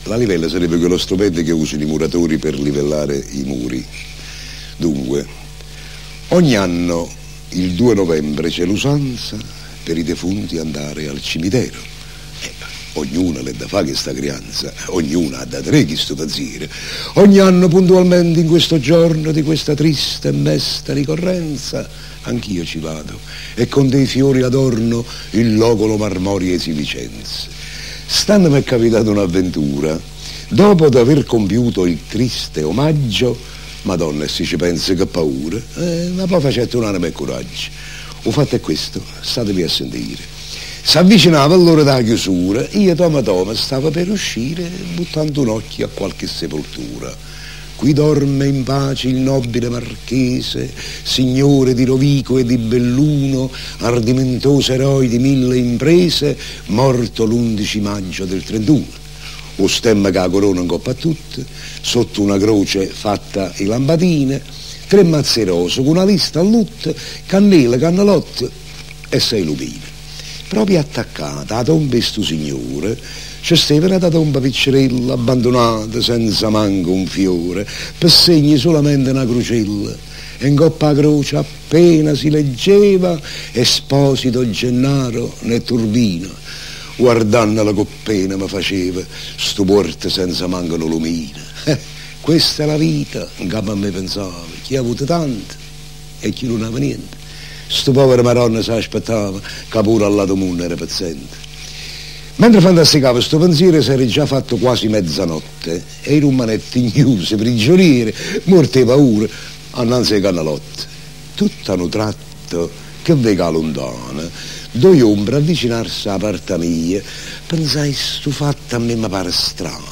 Ascolta la poesia dalla voce di Totò